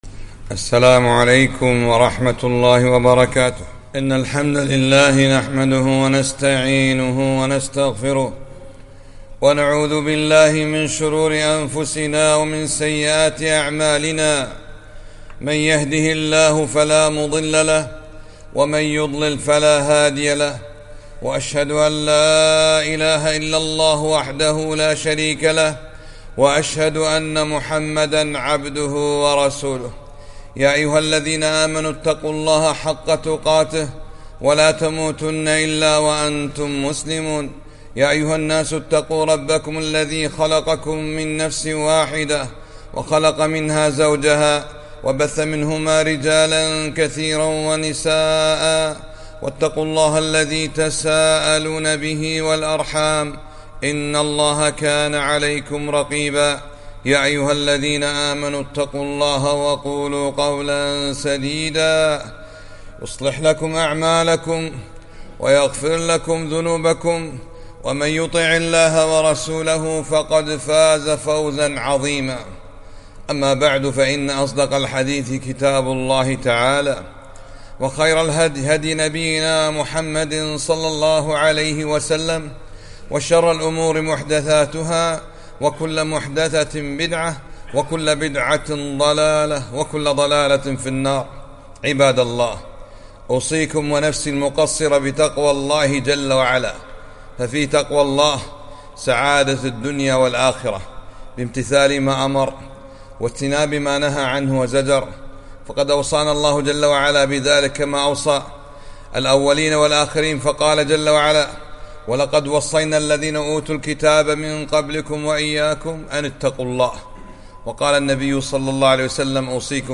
خطبة - إتباع الشهوات سبب لإضاعة الصلوات